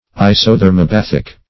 Isothermobathic \I`so*ther`mo*bath"ic\, a.